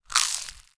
hbhunger_eat_generic.ogg